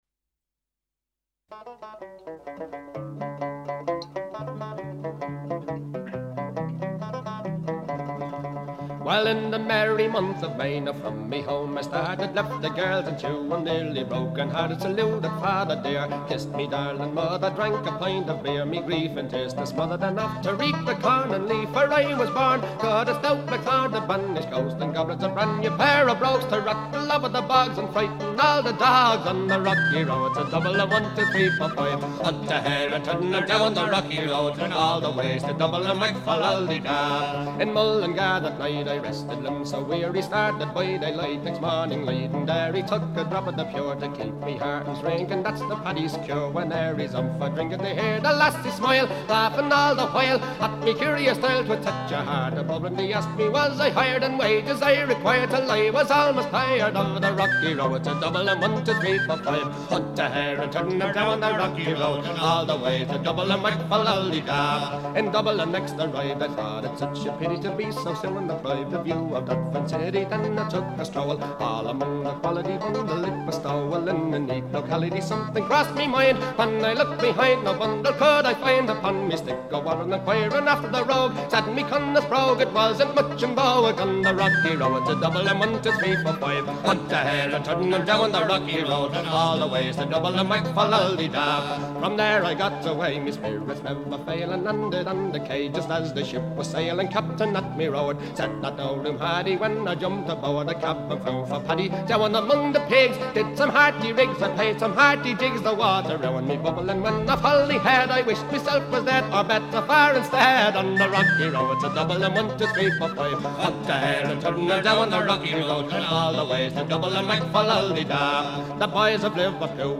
And so we come to what should have been my initial thought on how to celebrate St. Patrick’s Day – with real Irish music.
Let’s start with The Dubliners performing “The Rocky Road To Dublin.”